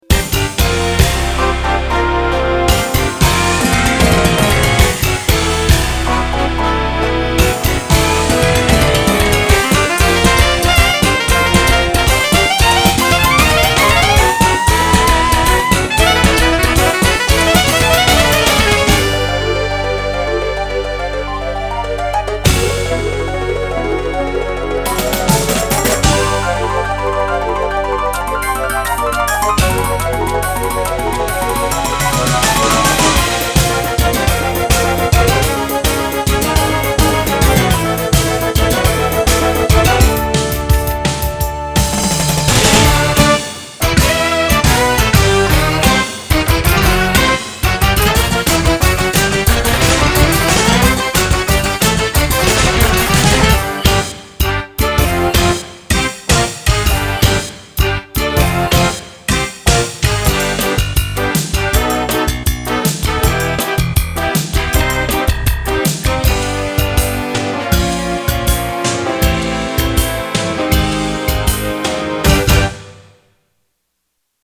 Jazz Fusion